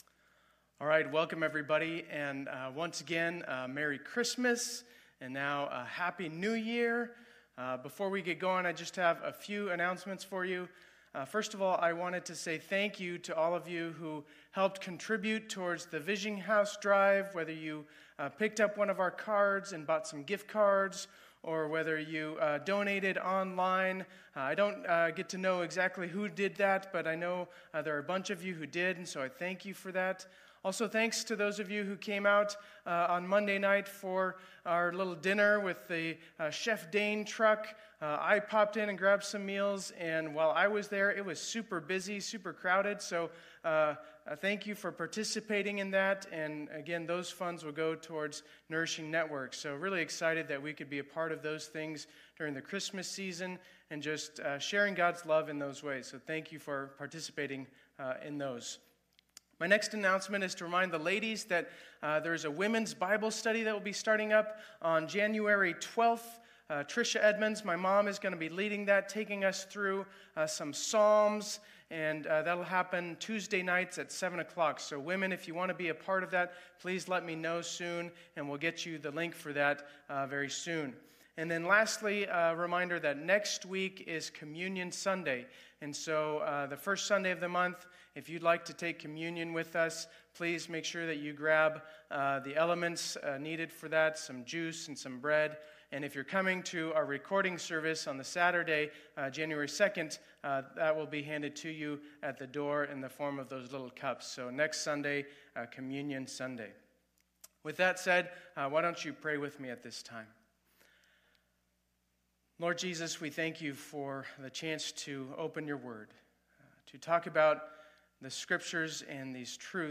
2020-12-27 Sunday Service
Intro, Teaching, and Lord’s Prayer